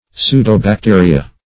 Pseudobacteria \Pseu`do*bac*te"ri*a\, n. pl. [Pseudo- +